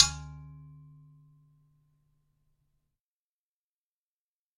描述：96kHz的录音，是用一把金属刀的刀柄敲击一把剑。钟声般的共鸣，很少有泛音
标签： 场记录 谐振 金属 金属
声道立体声